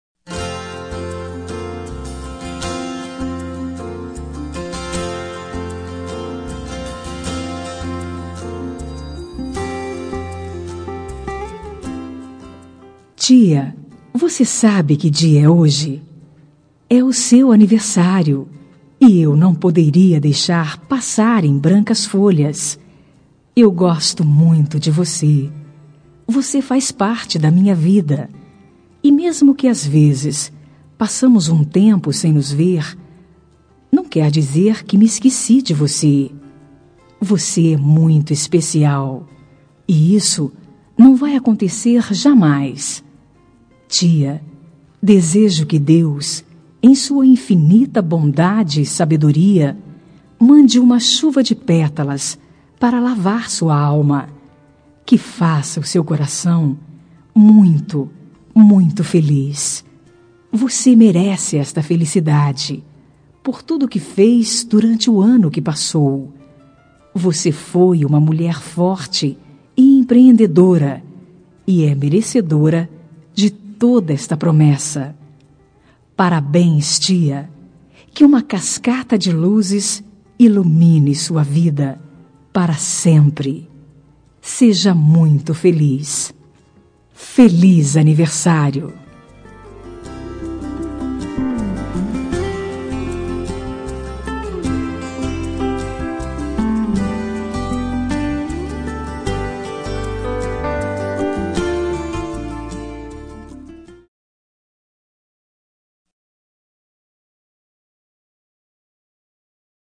Telemensagem Aniversário de Tia – Voz Feminina – Cód: 2002